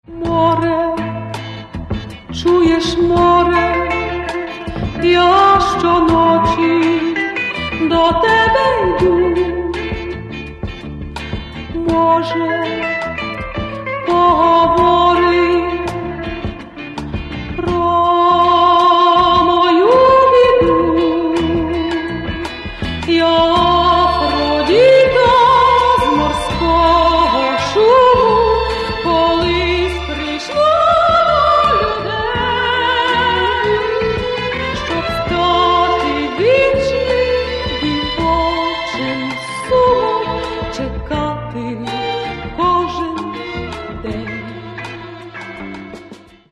Каталог -> Эстрада -> Певицы
К сожалению, качество записи некоторых песен не безупречно.